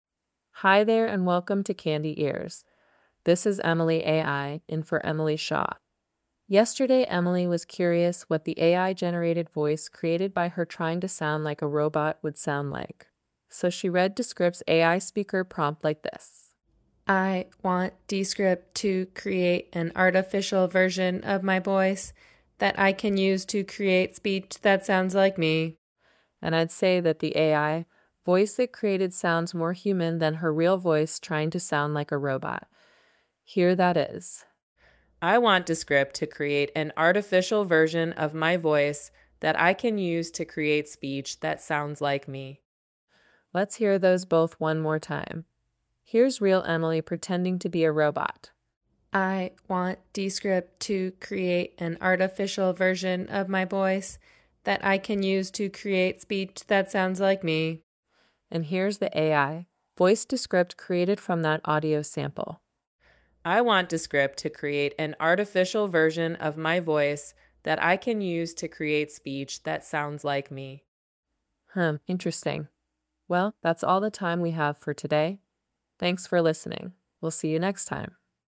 AI voices created using Descript.